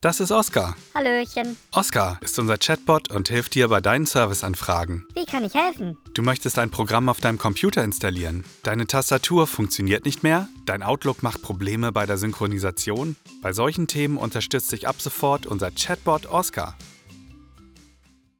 Explainer
Explainer Film, Milchstrassenfieber, Köln